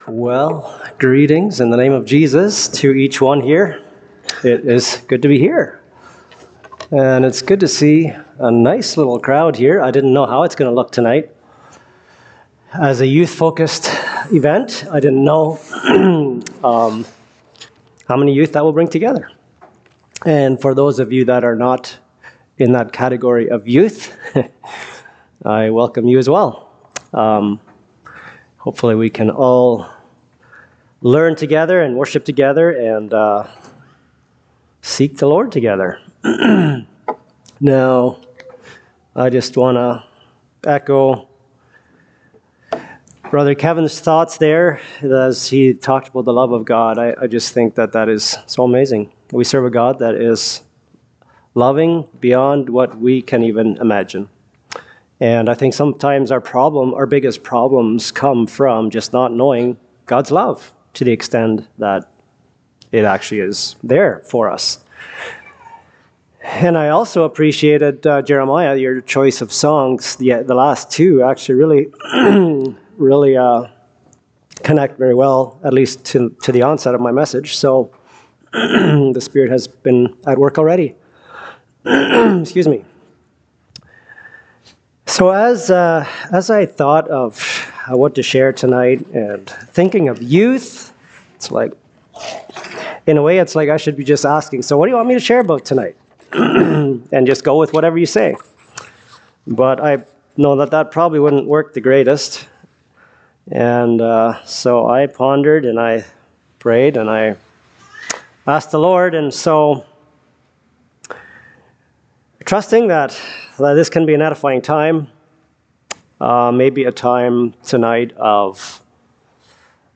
Friday Evening Youth Message
Service Type: Fellowship Weekend